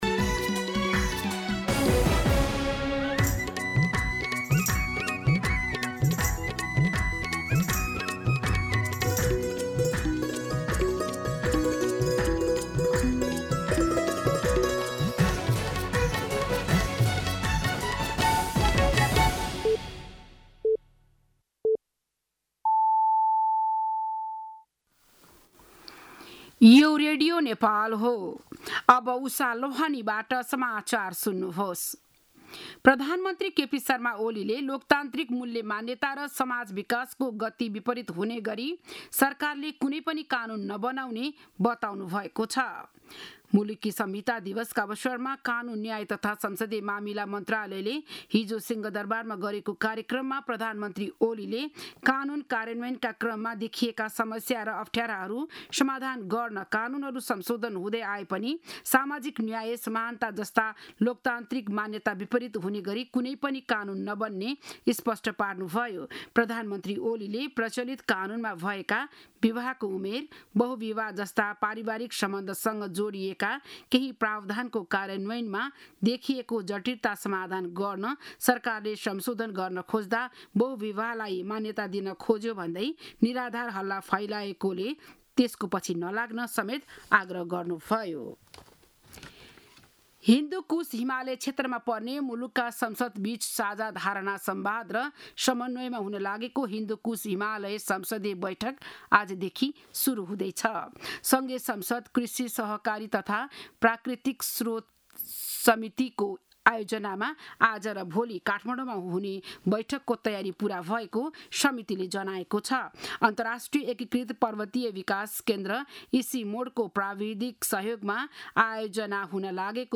बिहान ११ बजेको नेपाली समाचार : २ भदौ , २०८२
11am-News-02.mp3